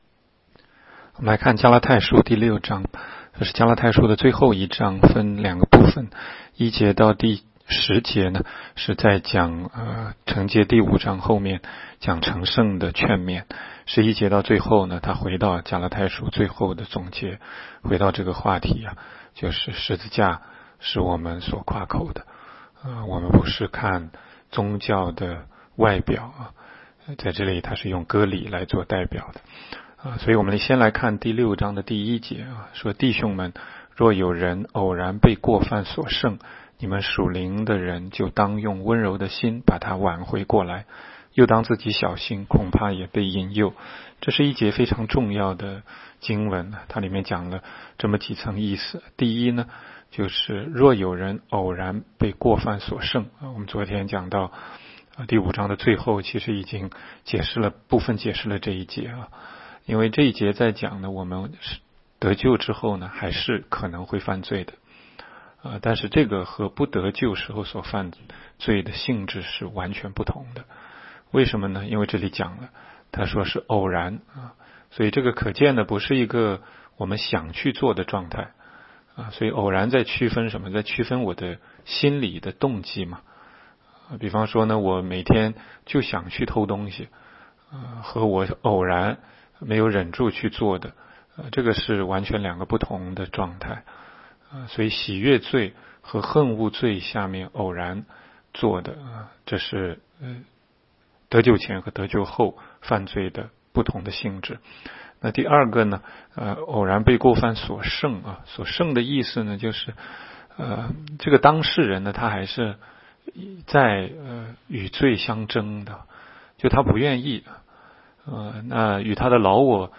16街讲道录音 - 每日读经-《加拉太书》6章